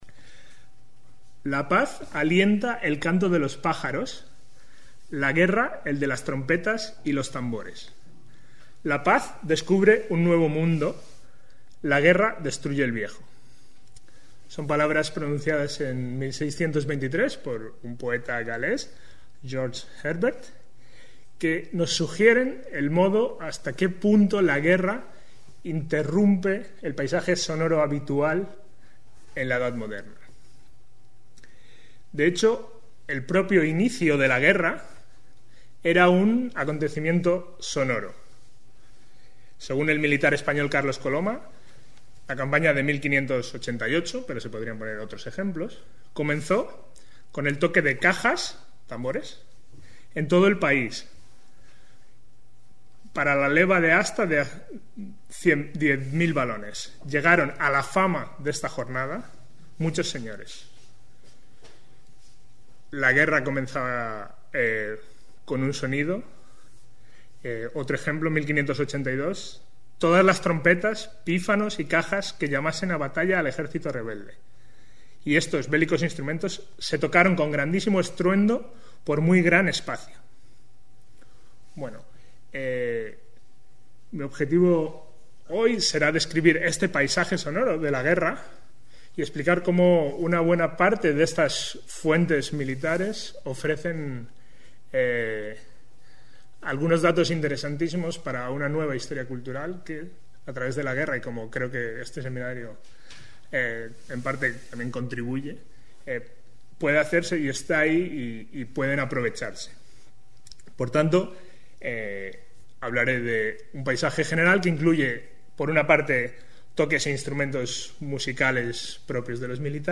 Esta presentación describe el paisaje sonoro y el efecto de la guerra sobre distintos oyentes. Mi exposición se centrará en las campañas continuadas que afectaron a Flandes y los Países Bajos entre 1568 y 1648 y utilizaré fuentes como relatos de soldados, relaciones de sucesos, manuales militares, historias generales del periodo, y obras de teatro de la época.